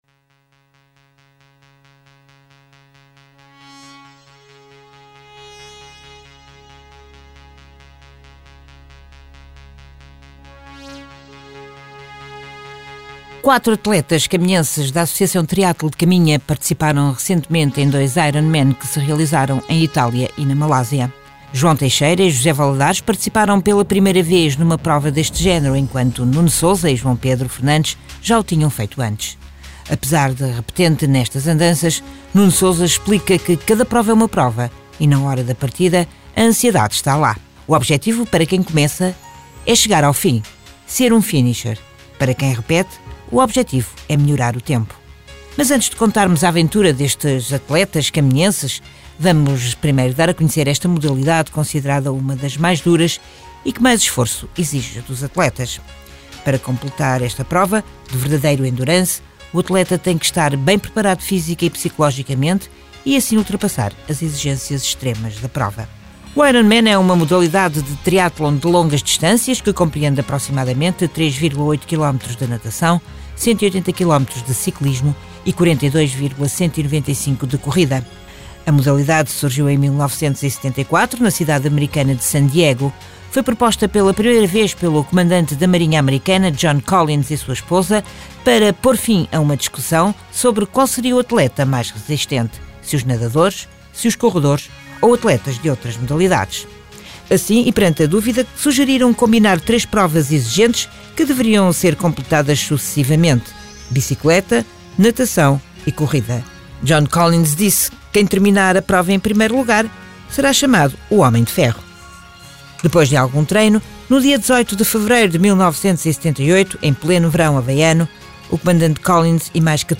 Numa entrevista conjunta ao Jornal C logo após a chegada de Itália (fim do texto) os 3 atletas, ainda em recuperação, contaram ao Jornal C como foi a preparação e a participação nesta prova que foi seguida com muito entusiasmo por muitos caminhenses.